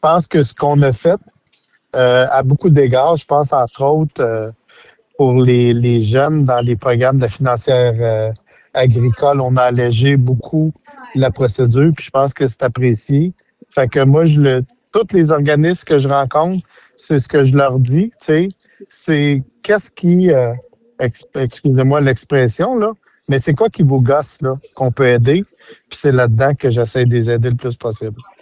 En entrevue, le ministre de l’Agriculture, des Pêcheries et de l’Alimentation a relaté un souhait qu’il avait pour cette nouvelle année.